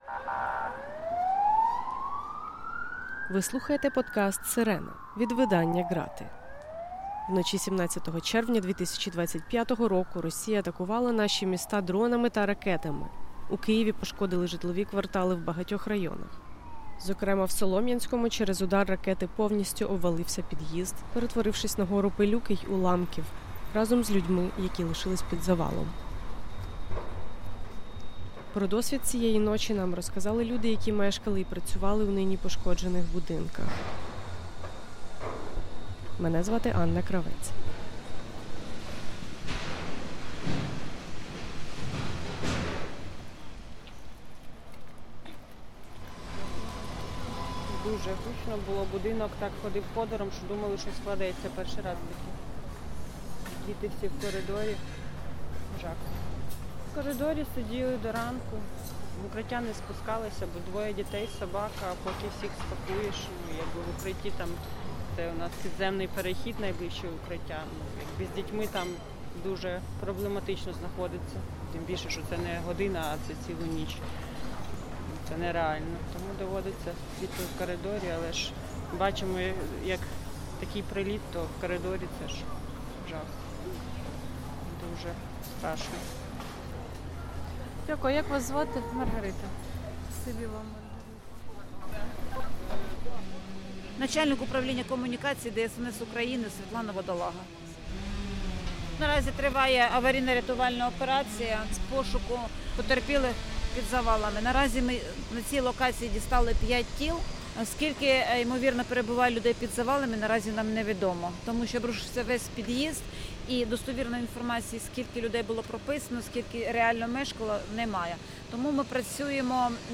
В епізоді присутня лайка.